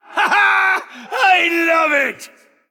Blackheart is a great announcer.
Blackheart_OutpostDestroy01.ogg